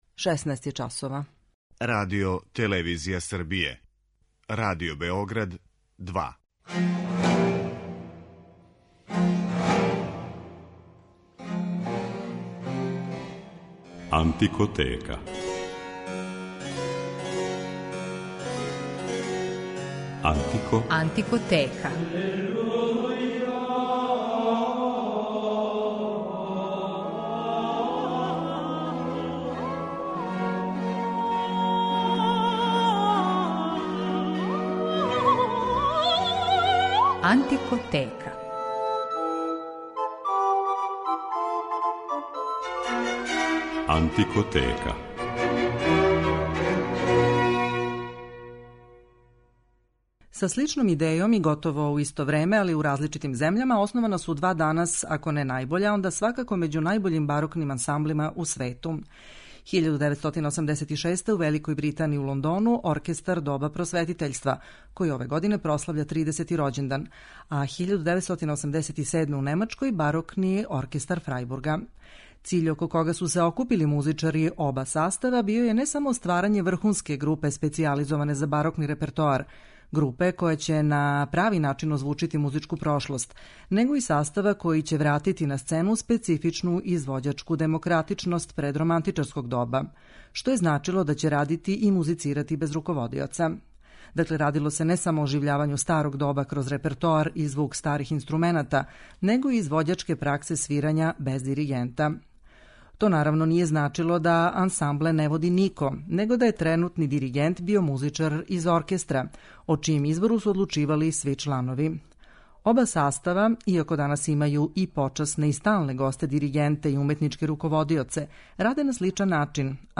Барокни ансамбли: 'Оркестар доба просветитељства' и 'Барокни оркестар Фрајбурга'
У данашњој емисији представиéмо ова два изузетна ансамбла кроз музику Георга Фридриха Хендла, Јохана Јозефа Фукса и Хенрија Персла. У рубрици Антикоскоп говорићемо о једној слици - о чувеном портрету Јохана Себастијана Баха који је урадио Елијас Готлиб Хаусман.